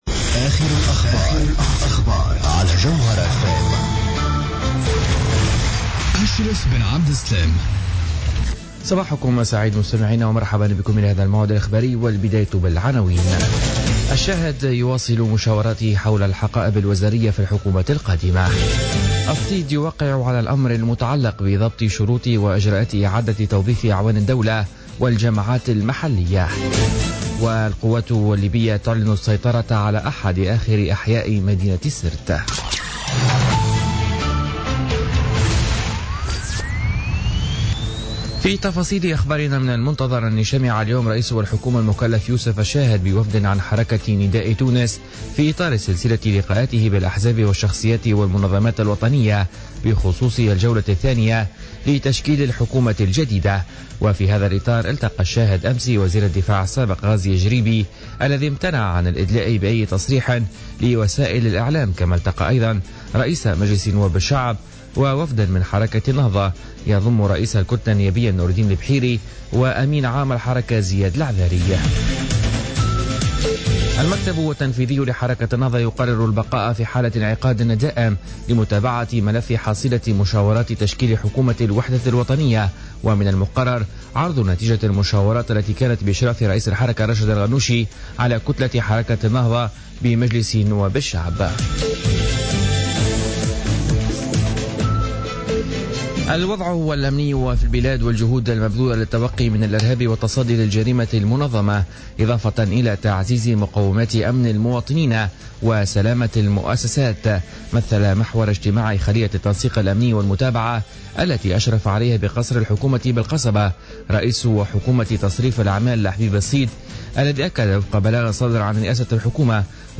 نشرة أخبار السابعة صباحا ليوم الأربعاء 17 أوت 2016